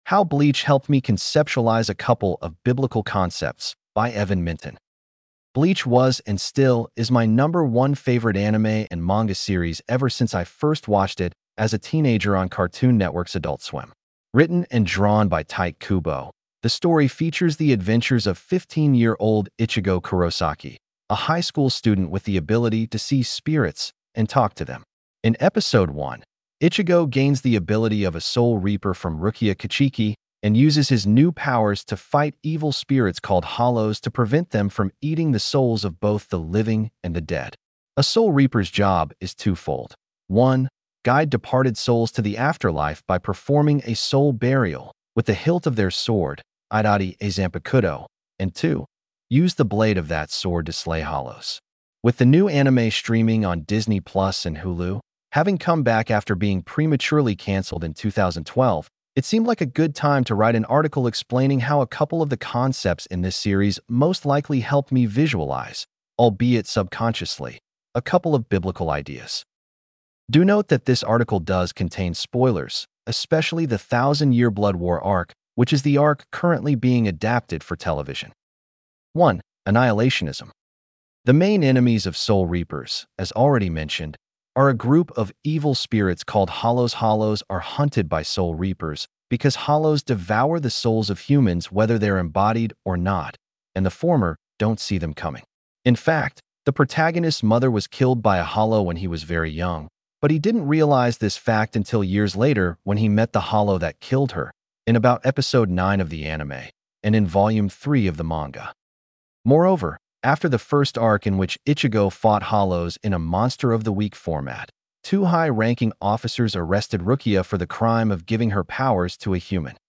Audio accessibility for this blog post is powered by Microsoft Text-to-Speech technology.